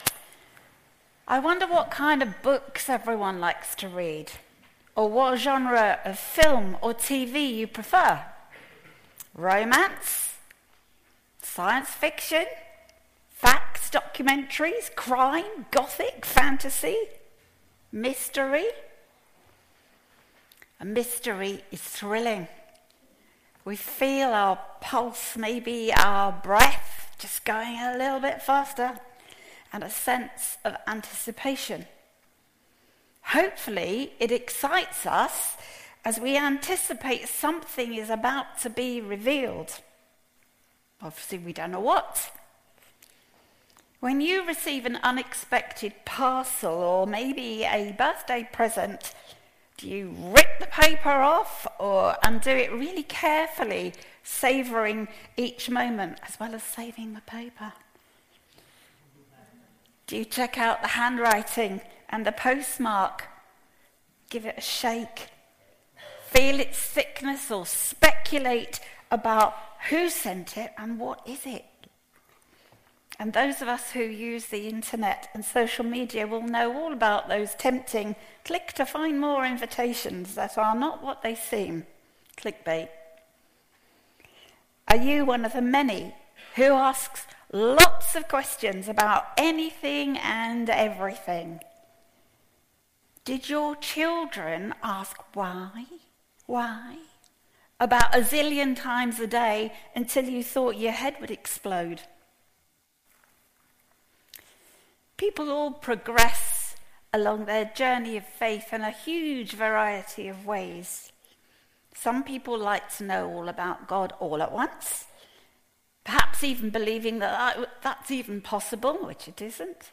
There is an audio version of the sermon also available.
05-26-sermon.mp3